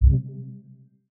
button_capture.mp3